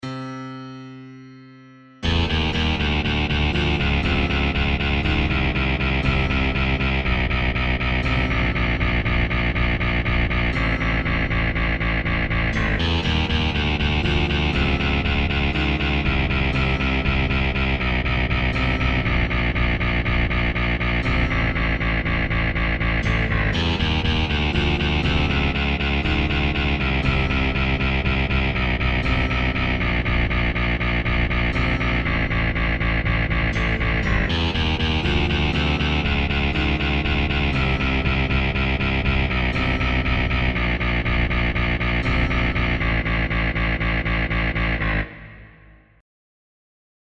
• Качество: 128, Stereo
громкие
без слов
клавишные
инструментальные
необычные